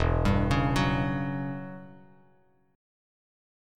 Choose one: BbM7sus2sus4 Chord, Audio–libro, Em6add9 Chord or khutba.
Em6add9 Chord